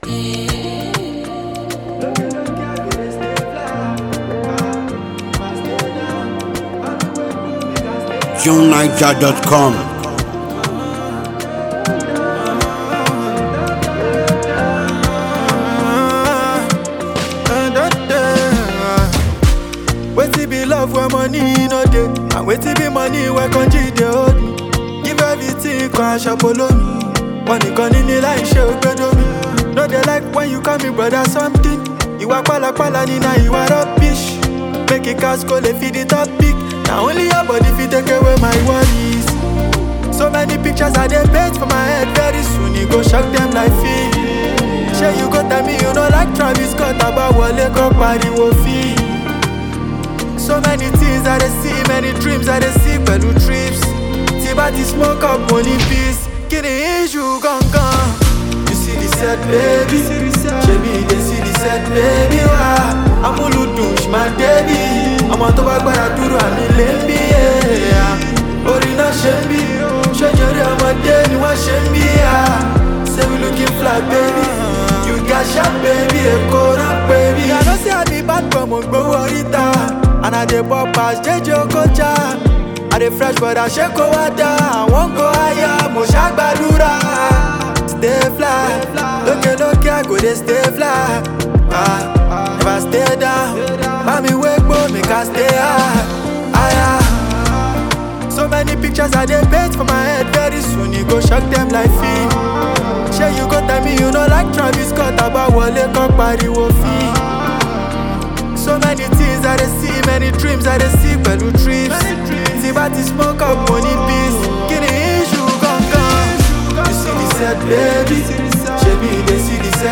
The seductive song